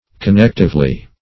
connectively - definition of connectively - synonyms, pronunciation, spelling from Free Dictionary Search Result for " connectively" : The Collaborative International Dictionary of English v.0.48: Connectively \Con*nect"ive*ly\, adv. In connjunction; jointly.